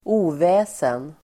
Uttal: [²'o:vä:sen]